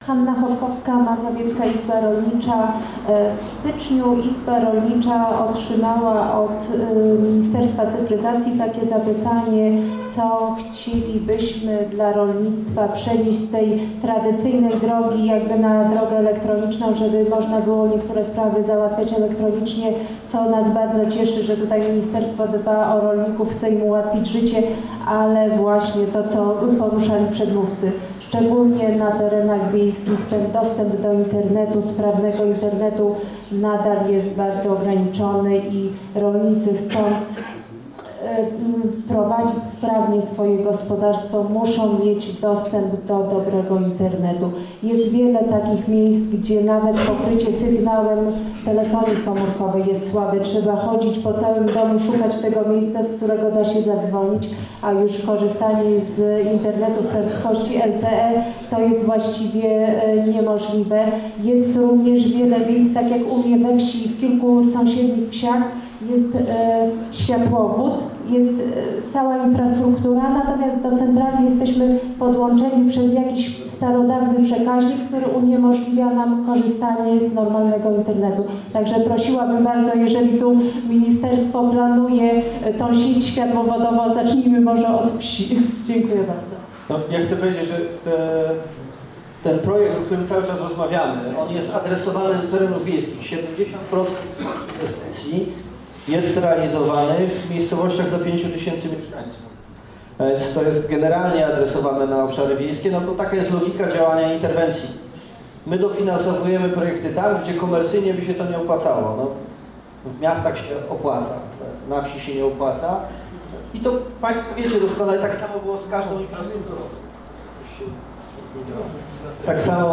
Liczba uczestników spotkania przerosła oczekiwania organizatorów, sala była szczelnie wypełniona, a dla wielu uczestników zabrakło miejsc siedzących.
Pytania-i-odpowiedzi-2.mp3